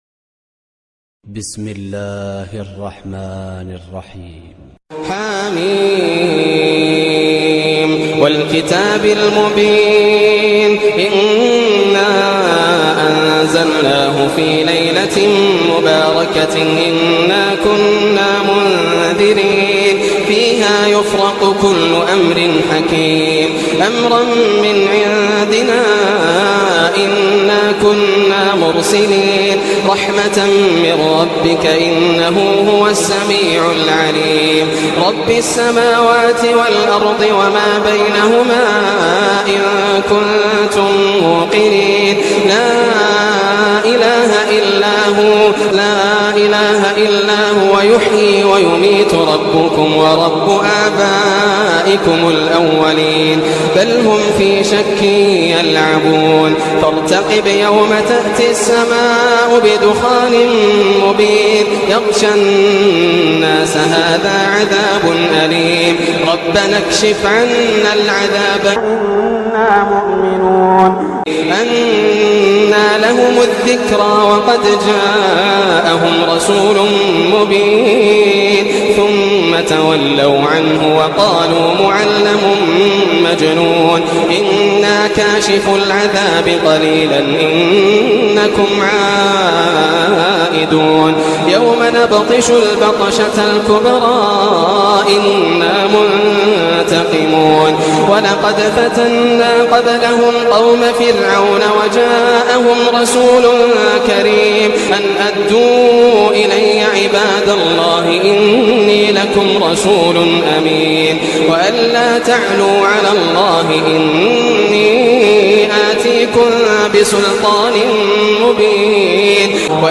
سورة الدخان > السور المكتملة > رمضان 1425 هـ > التراويح - تلاوات ياسر الدوسري